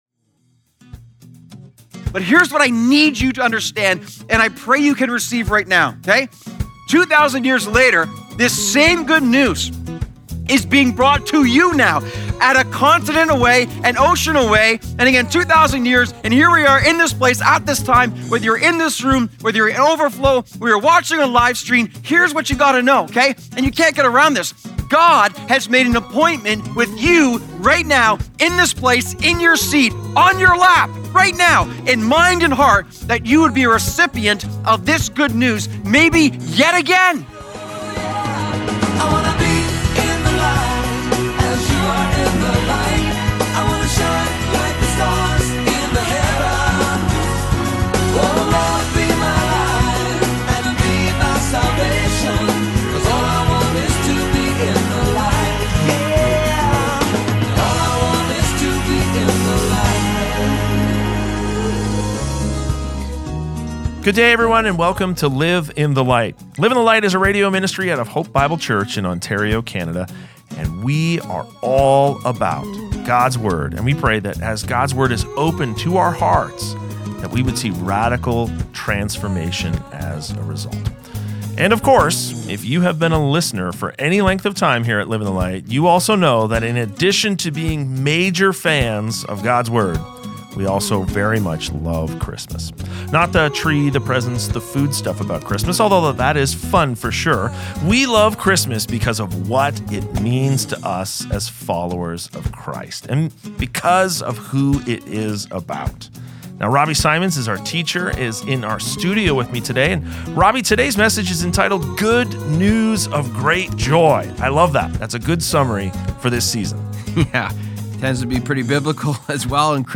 A Live in the Light Christmas Message